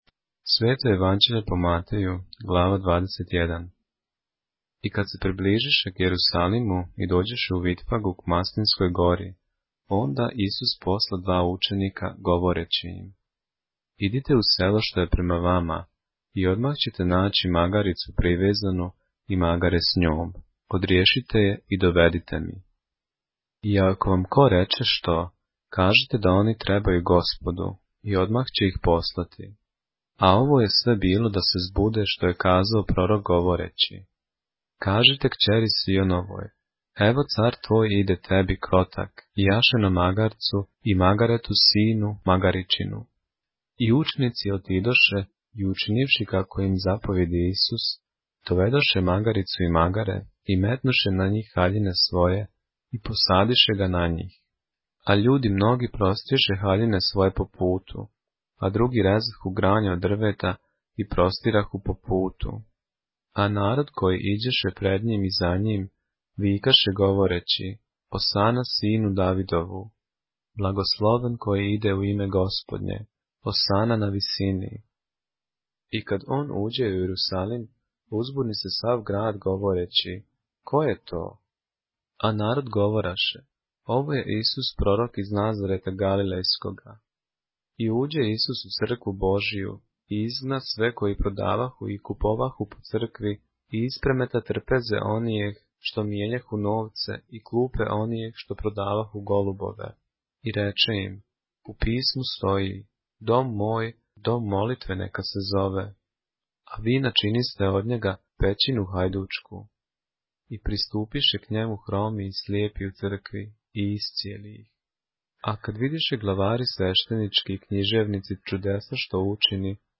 поглавље српске Библије - са аудио нарације - Matthew, chapter 21 of the Holy Bible in the Serbian language